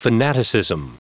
Prononciation du mot fanaticism en anglais (fichier audio)
Prononciation du mot : fanaticism